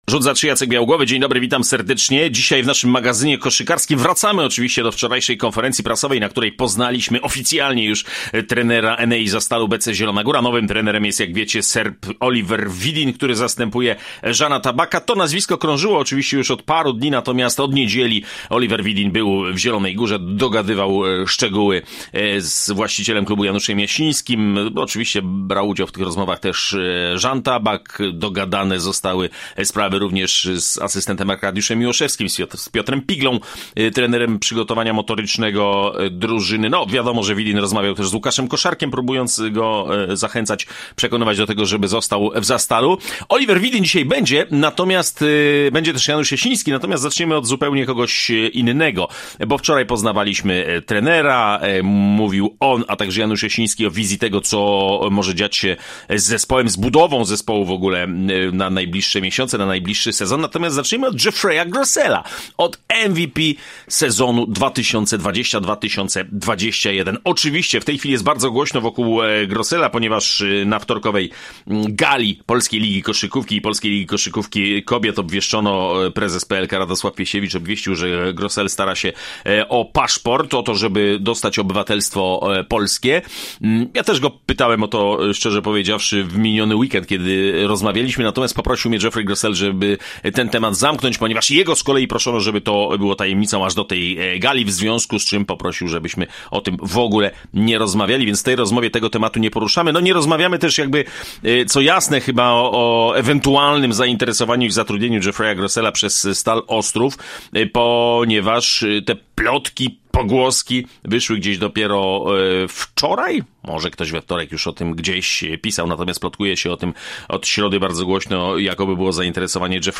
Rzut za trzy to dzisiaj trzy rozmowy.